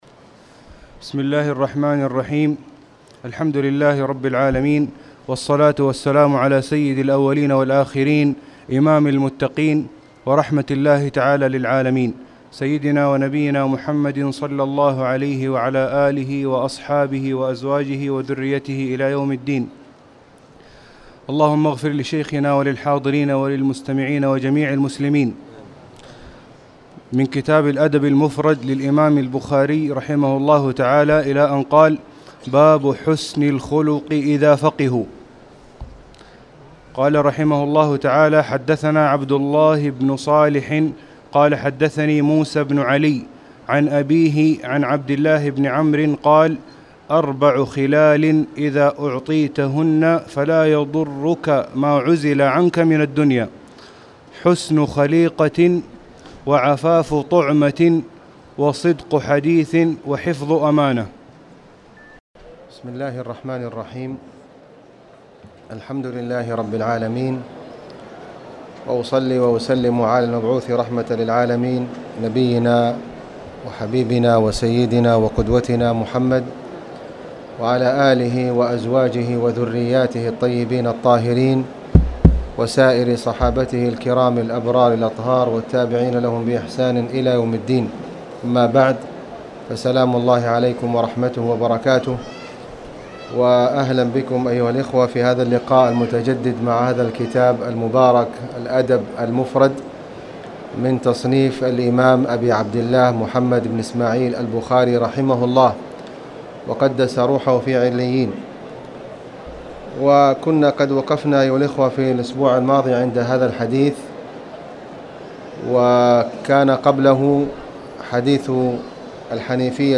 تاريخ النشر ٤ شعبان ١٤٣٨ هـ المكان: المسجد الحرام الشيخ: خالد بن علي الغامدي خالد بن علي الغامدي باب حسن الخلق اذا فقهوا